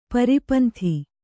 ♪ pari panthi